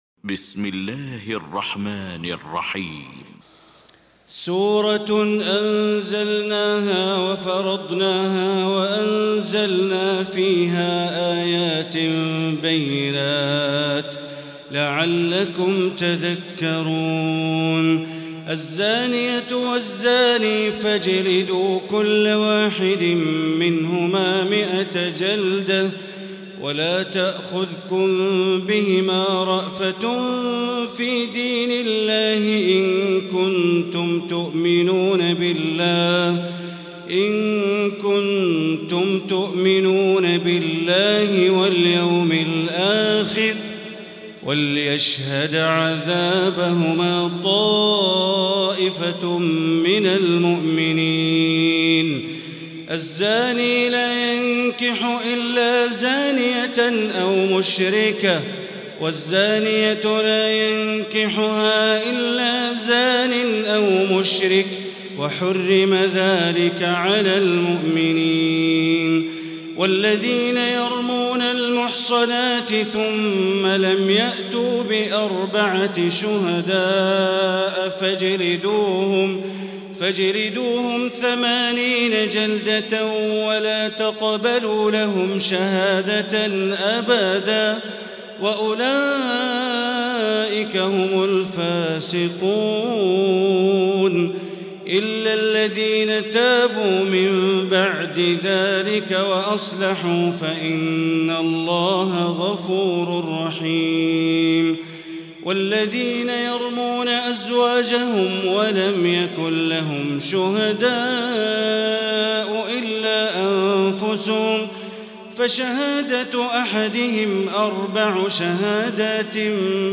المكان: المسجد الحرام الشيخ: معالي الشيخ أ.د. بندر بليلة معالي الشيخ أ.د. بندر بليلة النور